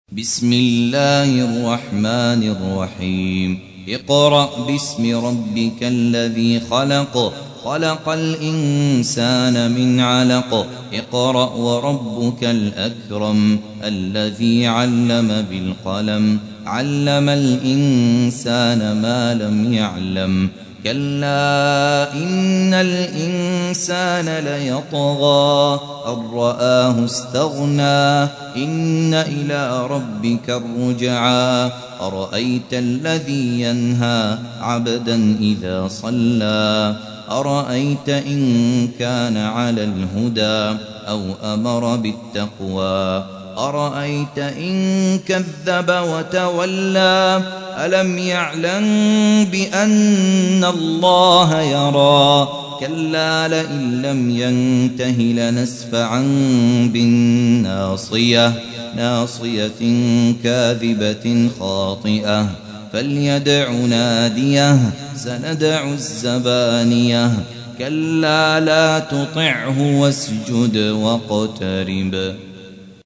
تحميل : 96. سورة العلق / القارئ خالد عبد الكافي / القرآن الكريم / موقع يا حسين